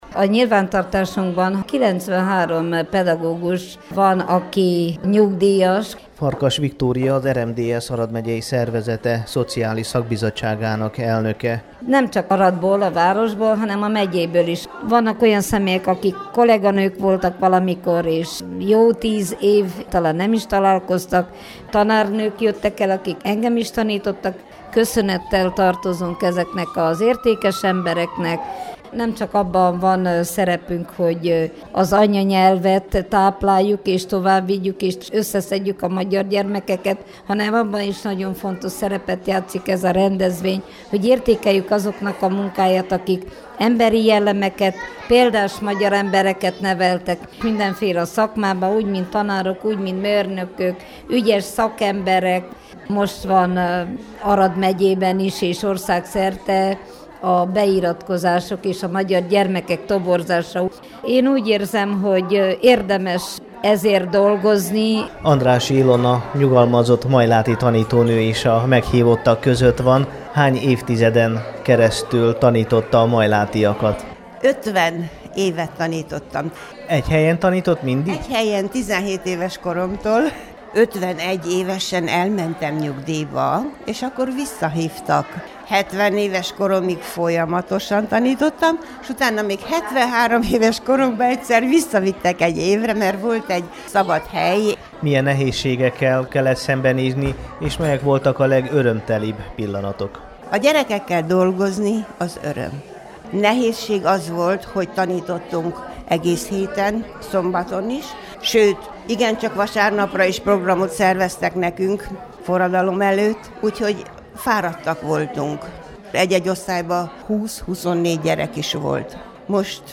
Nyugdíjas Arad megyei magyar tanító- és tanárnőket köszönöttek a hétvégén a nőnap alkalmából az aradi RMDSZ-székházban péntek délután.